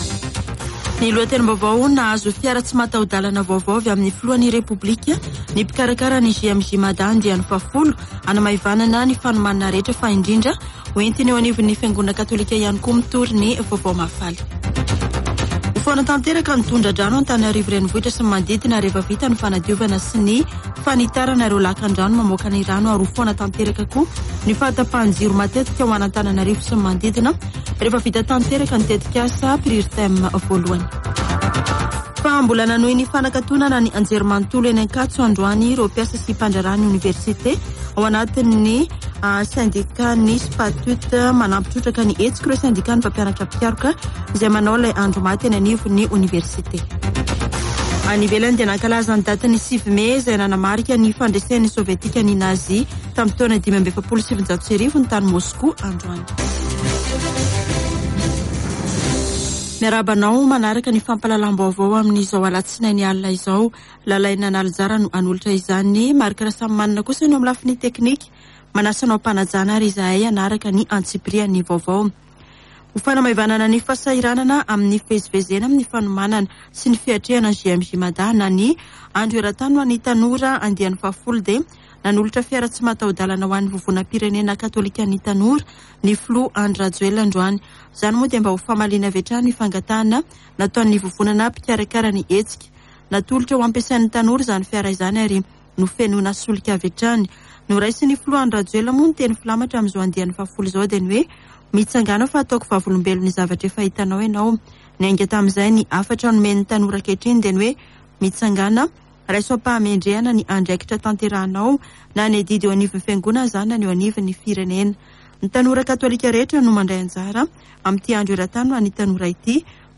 [Vaovao hariva] Alatsinainy 9 mey 2022